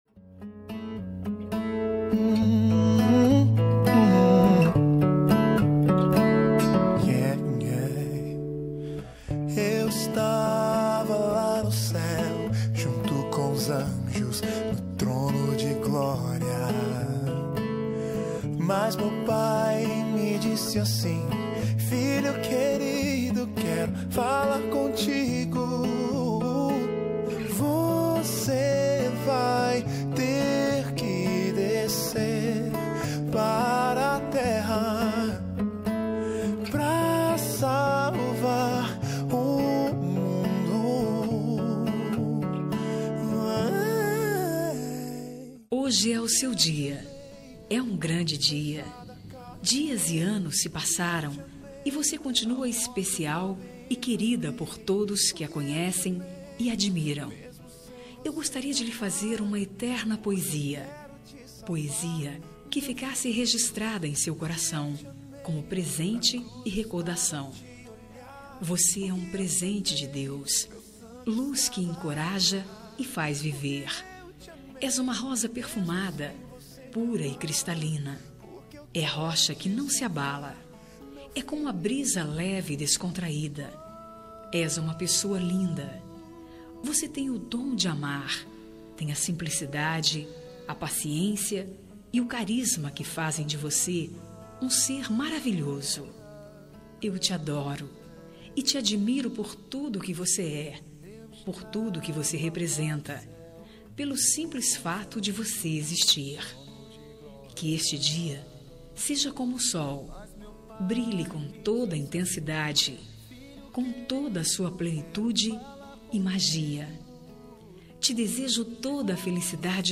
Aniversário de Irmã – Voz Feminina – Cód: 90006 – Linda
90006-aniv-irma-gosel-fem.m4a